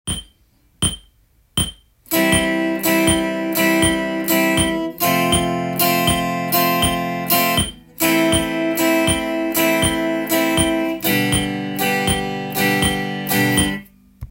跳ねたリズムでも裏拍練習
跳ねたリズム（シャッフル）の時にも裏拍が出てくからです。
ストロークをしたと時にメトロノームがかなり近い距離で鳴っていれば
跳ねたリズムの裏拍が完成します。